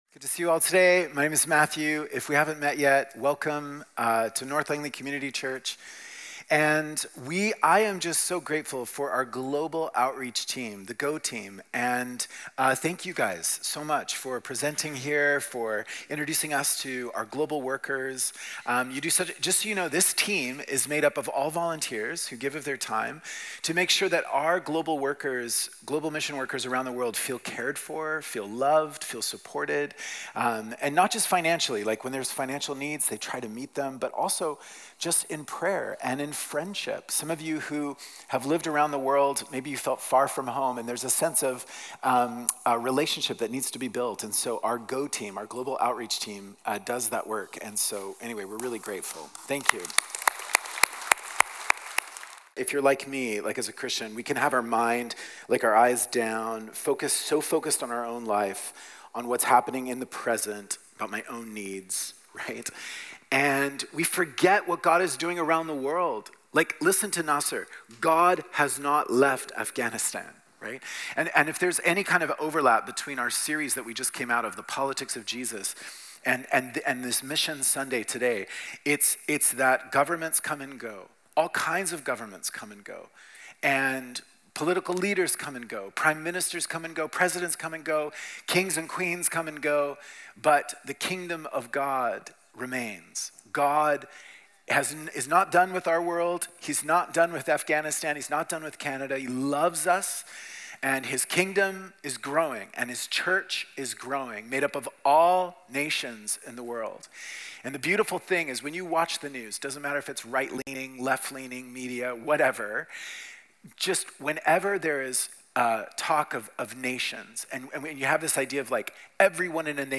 Walnut Grove Sermons | North Langley Community Church